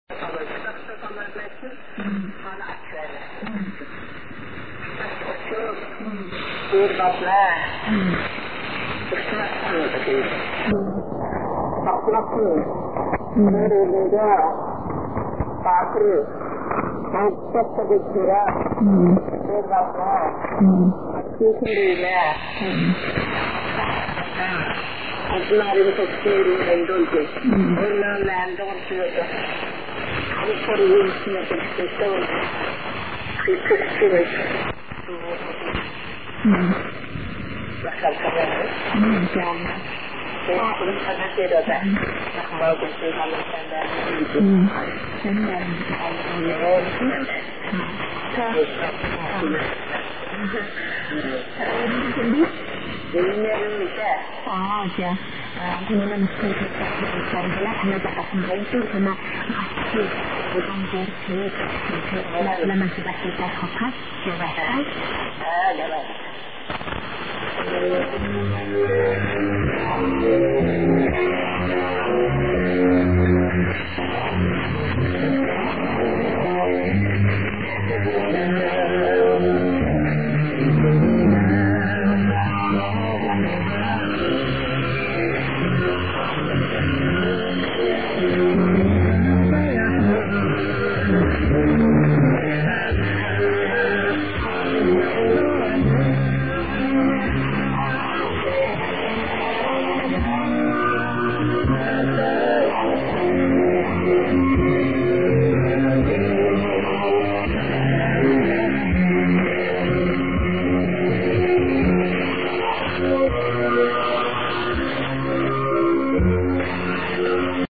DXPedition Itatiaiuçu-MG JAN-2008
ICOM IC-R75 c/DSP + Ham Radio Deluxe
02 Antenas Super KAZ 90 graus uma da outra NORTE-SUL E LESTE-OESTE